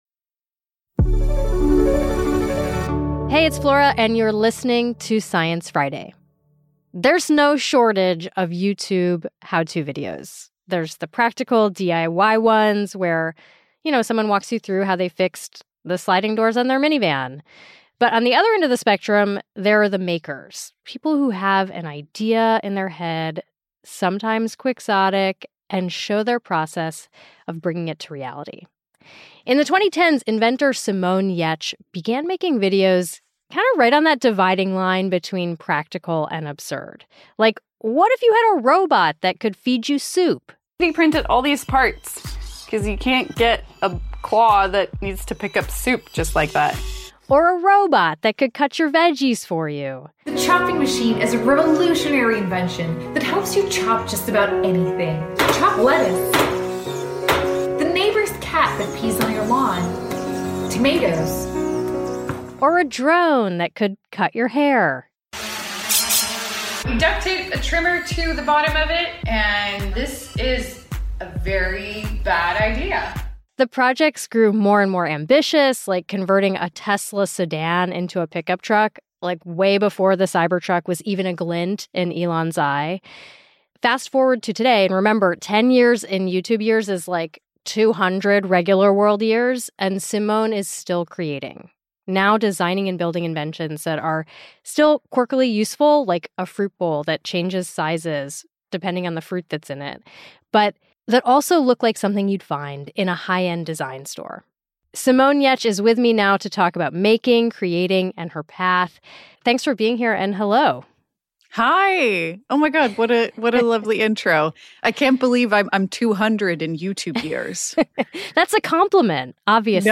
In the 2010s, inventor Simone Giertz (pronounced “Yetch”) began making videos that straddled the line between practical and absurd.